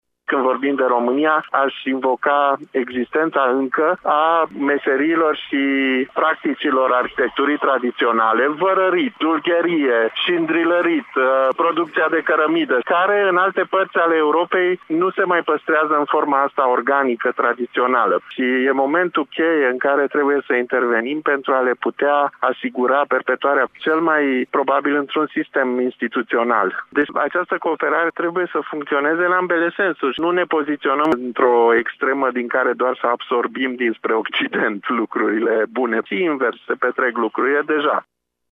Este una din concluziile conferinței pe teme de patrimoniu desfășurate la Sighișoara în perioada 11-13 aprilie.
Directorul Institutului Național al Patrimoniului, Ștefan Bâlici: